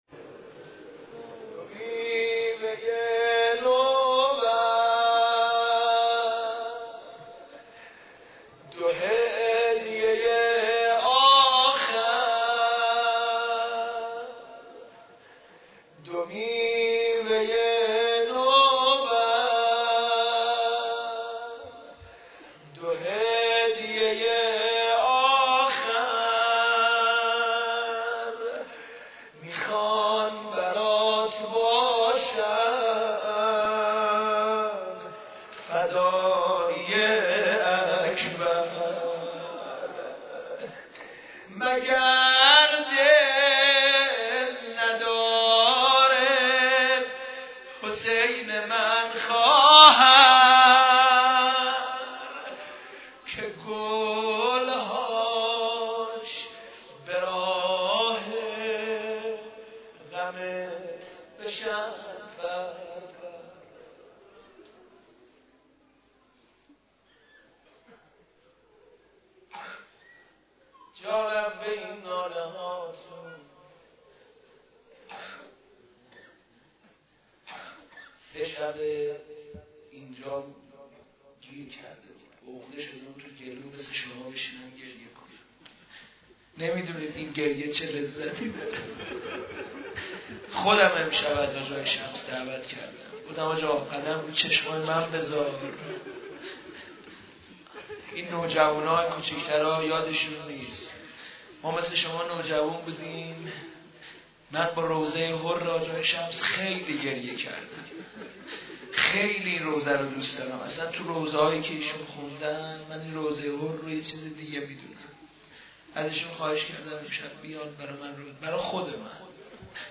روضه مداح اهل بیت استاد
مداحی و نوحه